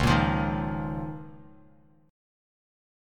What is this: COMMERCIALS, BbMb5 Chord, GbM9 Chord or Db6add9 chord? Db6add9 chord